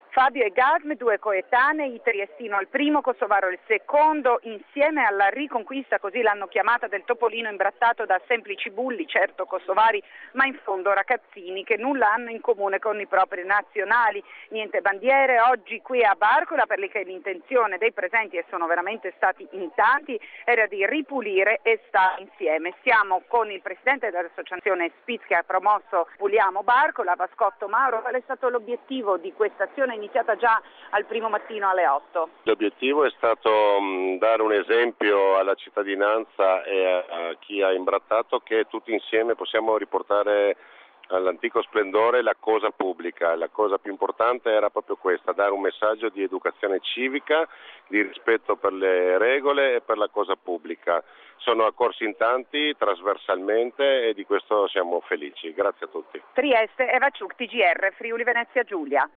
GR Regionale di domenica 28 giugno 2015 ore 12:15